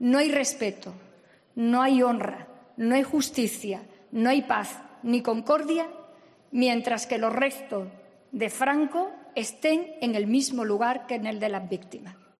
Carmen Calvo defiende el decreto que permitirá la exhumación de Franco: "Teníamos que haberlo resuelto antes"